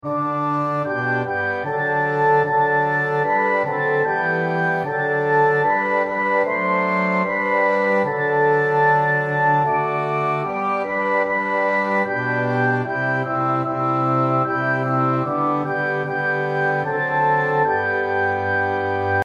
Hymns of praise
Orchestral Version